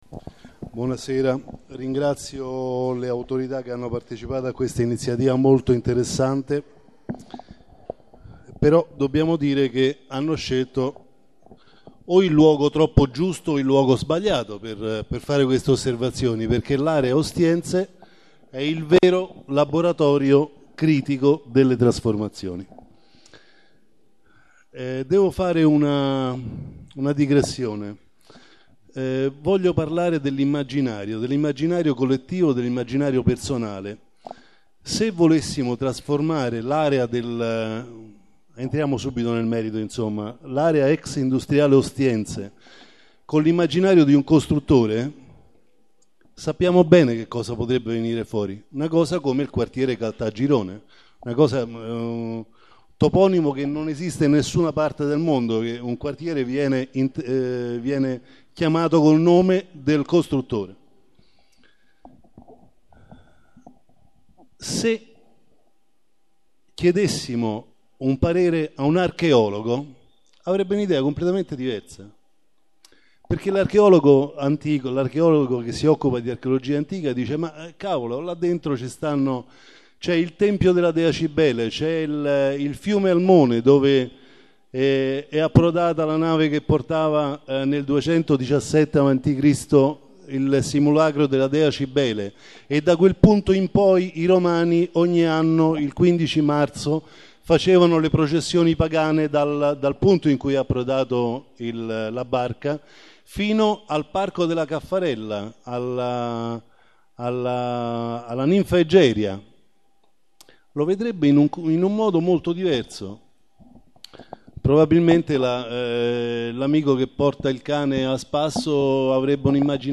Registrazione integrale dell'incontro svoltosi il 7 luglio 2014 presso l'Urban Center di Via Niccolò Odero